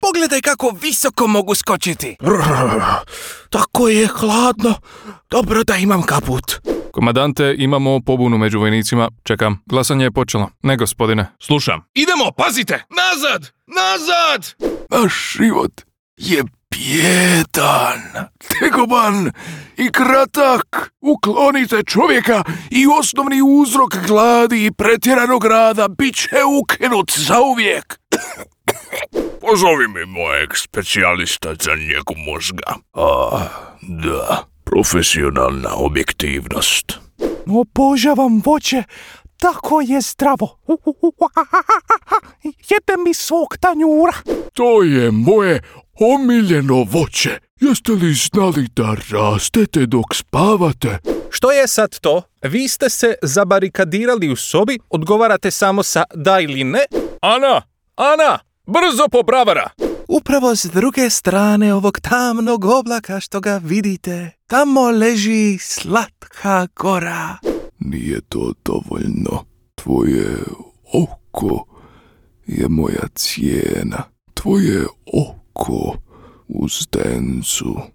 Comercial, Seguro, Amable